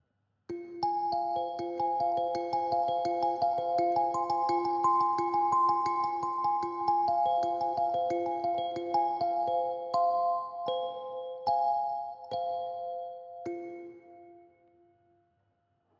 Kalimba – ein Zauberinstrument
Es wird aus Holz hergestellt und der Klang entsteht durch die schmalen Metallstäbchen.
Die Holzscheibe dient als Resonanzkörper und es erklingen wirklich wunderbare, weiche Klänge.
Die Klänge sind sehr sanft uns wirken sehr beruhigend auf uns Erwachsene und auch auf Kinder.
Kalimba-1.mp3